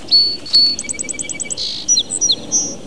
Song Sparrow
sparrowsong224.wav